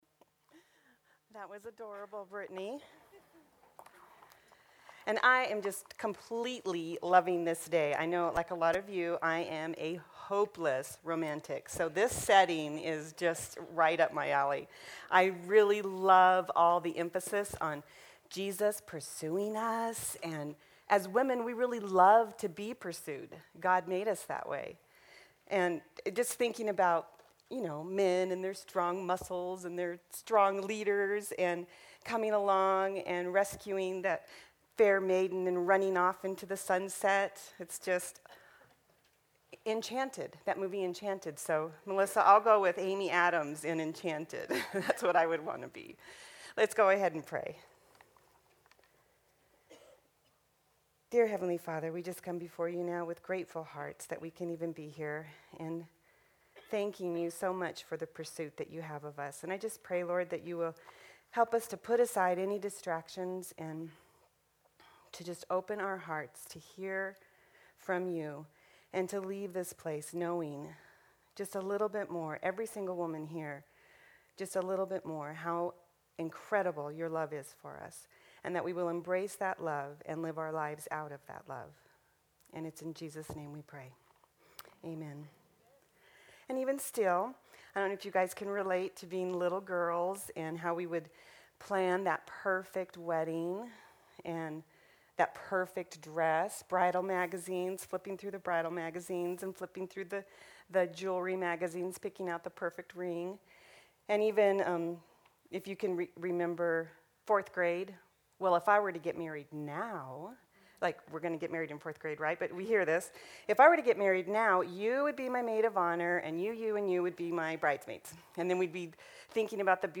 Women's Day Conference 2016: All You Need is Love